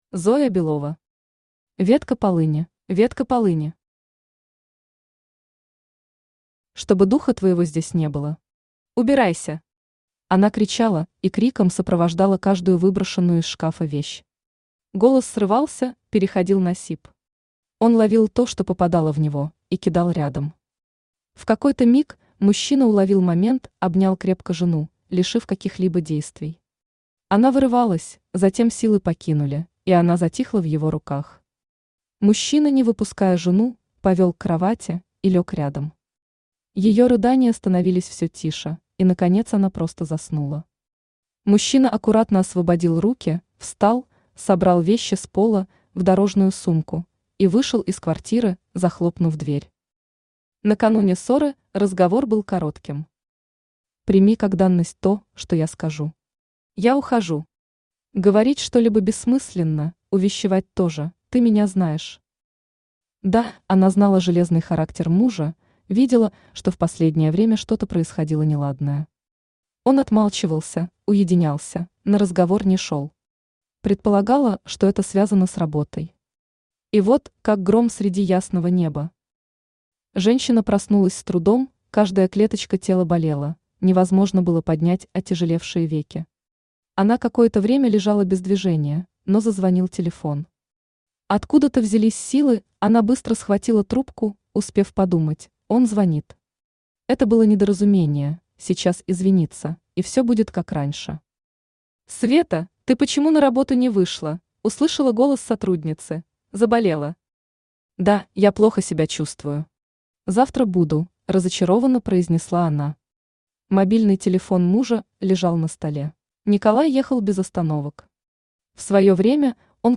Аудиокнига Ветка полыни | Библиотека аудиокниг
Aудиокнига Ветка полыни Автор Зоя Белова Читает аудиокнигу Авточтец ЛитРес.